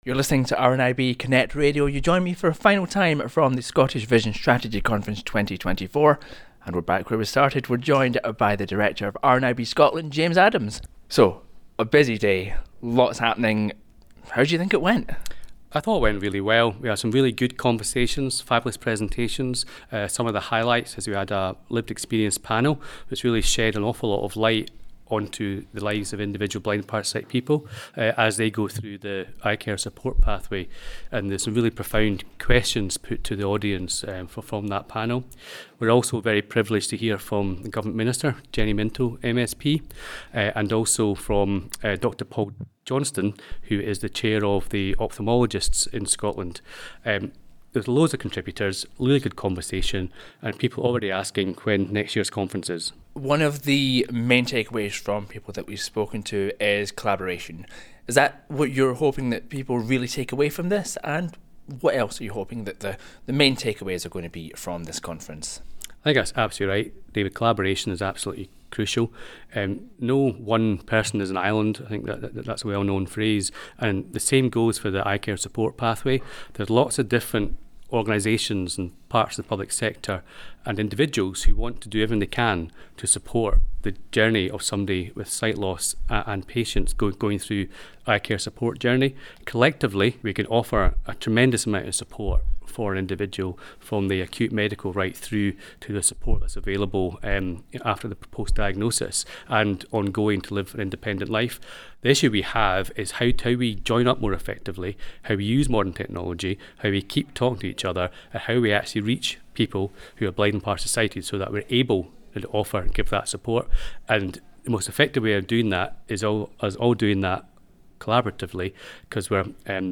The Scottish Vision Strategy Conference took place on Wednesday 20 March 2024 in Edinburgh.